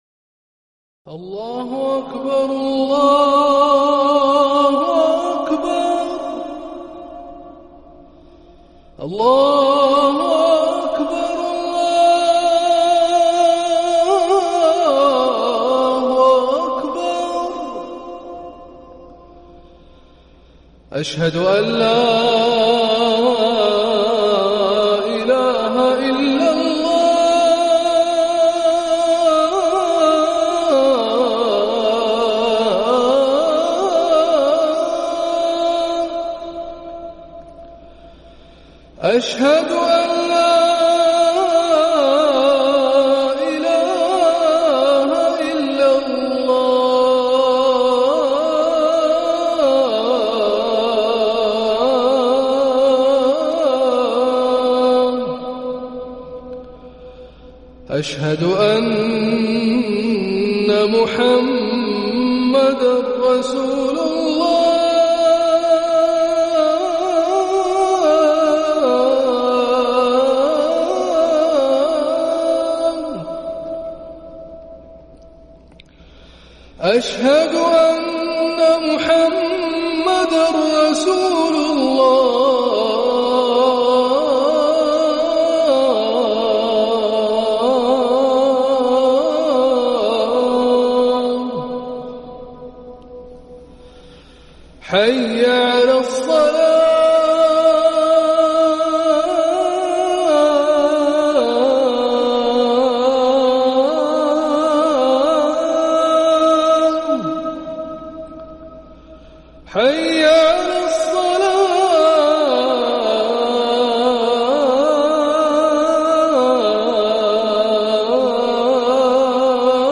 اذان المغرب
ركن الأذان